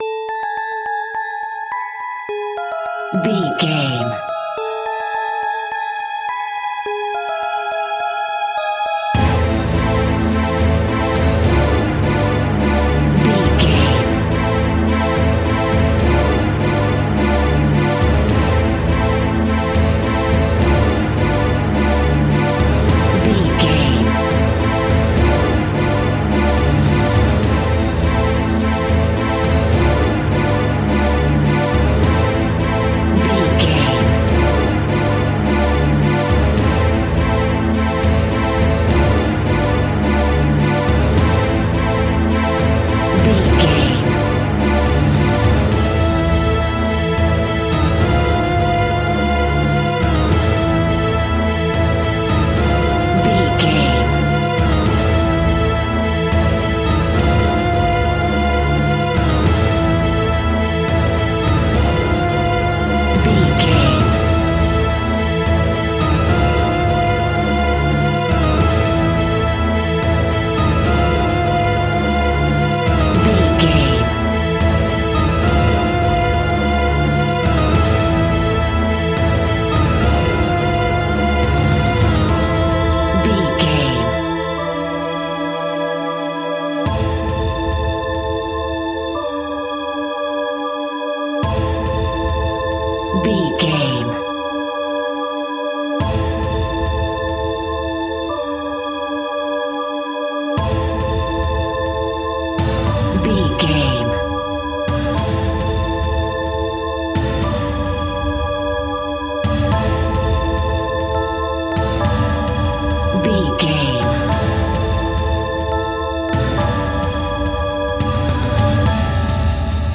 In-crescendo
Aeolian/Minor
scary
ominous
suspense
haunting
eerie
strings
drums
electric piano
synthesiser
percussion
horror